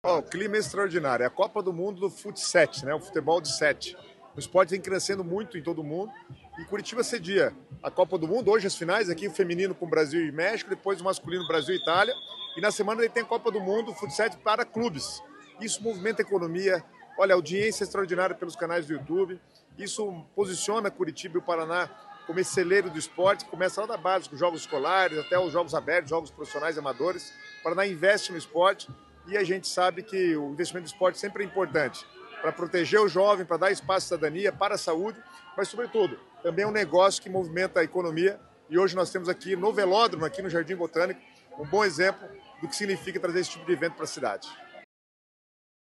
Sonora do secretário das Cidades, Guto Silva, sobre a Copa do Mundo de Futebol 7 em Curitiba